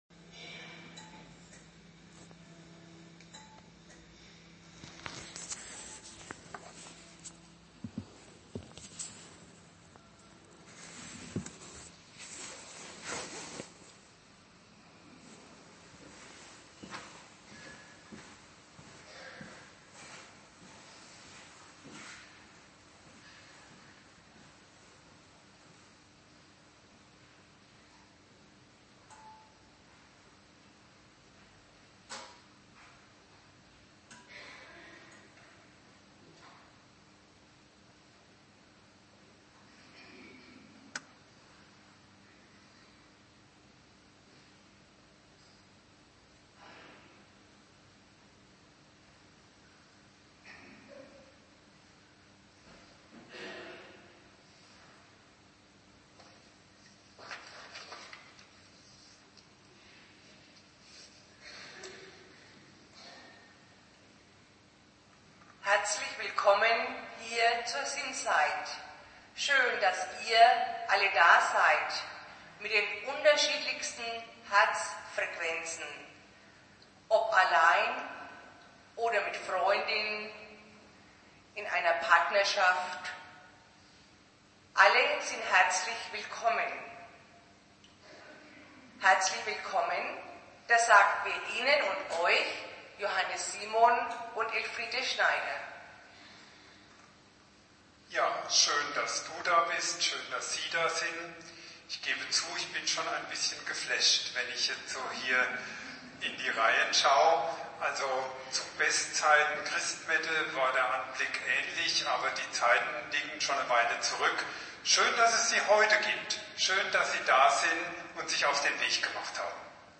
Sinnzeit – Der etwas andere Gottesdienst für Zweifler, Ungläubige und andere gute Christen
Da nicht alle live dabei sein konnten, haben wir einen einfachen Tonmitschnitt über die jeweilige Kirchenanlage hier zum Reinhören: